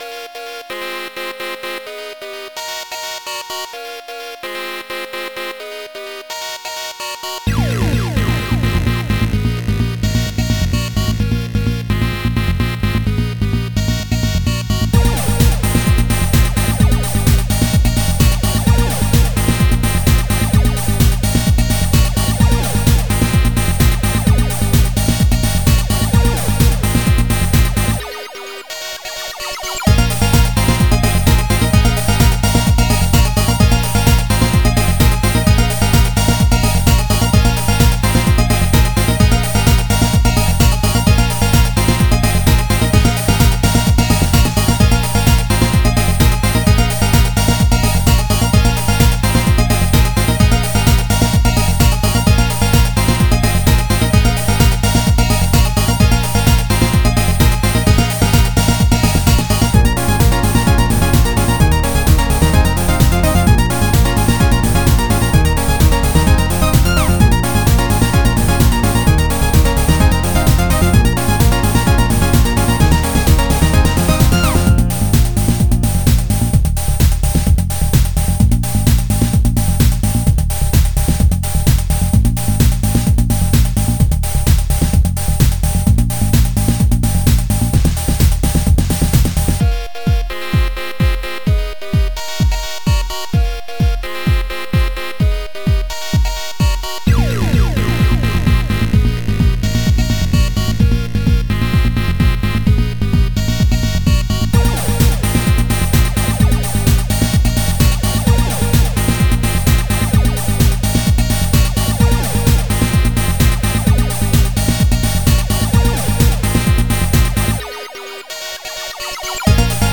this is the music that plays in level 1
It’a a real banger!